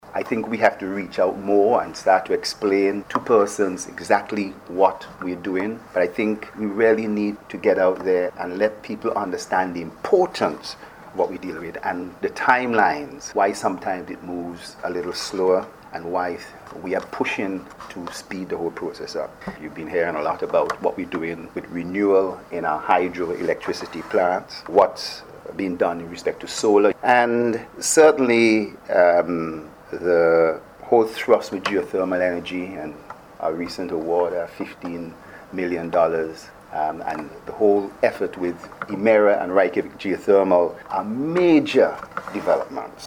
Dr. Thompson was speaking at the start of a Regional Workshop on: Simulation Tools for Energy Efficiency in Caribbean Buildings, which opened here on Tuesday.